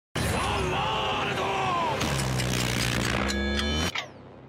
Play, download and share Za Warudo with SFX original sound button!!!!
za-warudo-with-sfx.mp3